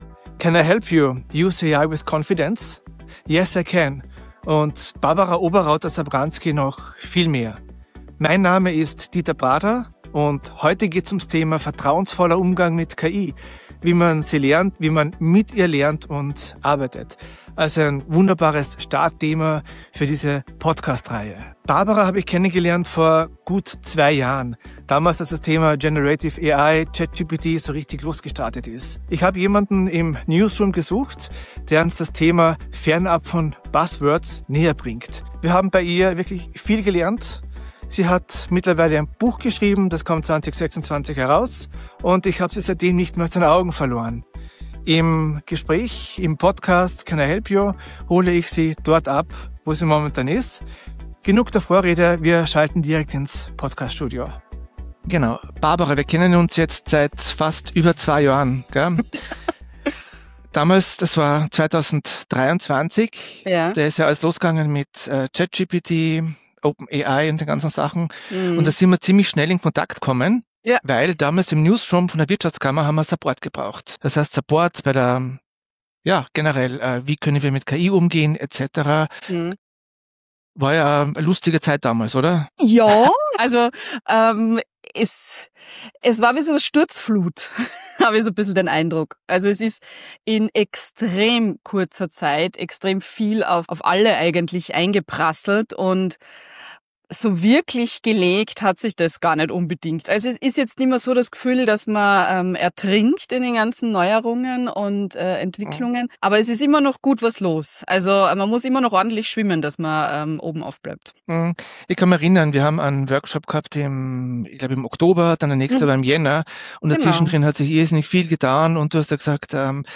Im Mittelpunkt steht nicht der nächste Hype oder der Vergleich einzelner Tools, sondern die Frage, wie KI sinnvoll, souverän und realistisch eingesetzt werden kann – im Arbeitsalltag, in Organisationen und im persönlichen Lernen. Das Gespräch spannt den Bogen von digitalen Newsrooms über Kommunikation und Wissensarbeit bis hin zu Medienwandel und digitaler Transformation.